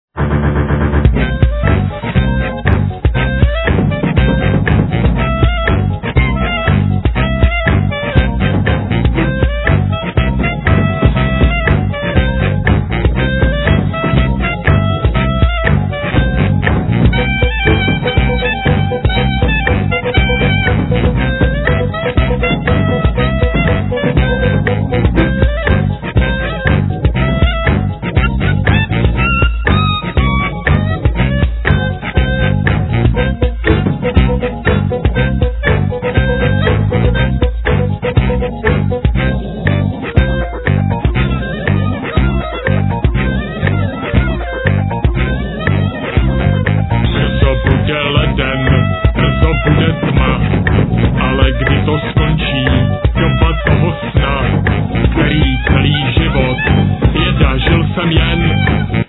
Bass guitar, Sequencer, Sbor
Trumpet
Violin